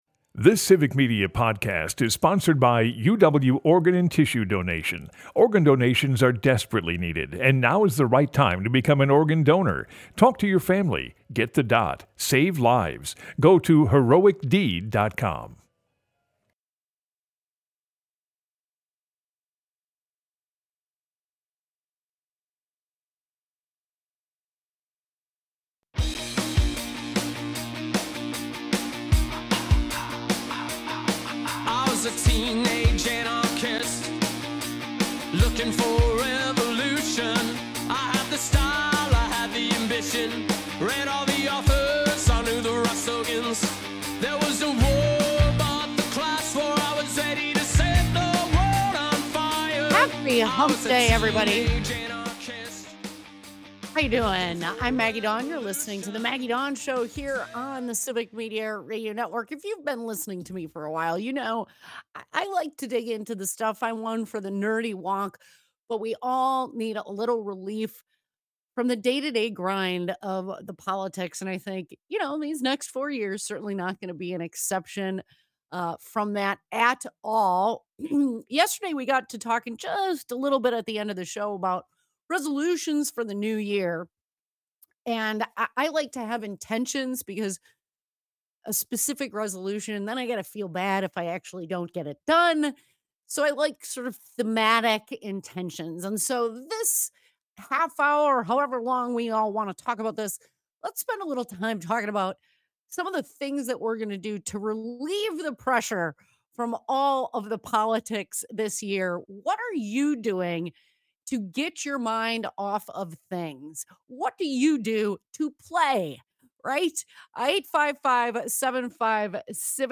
Callers join the fun.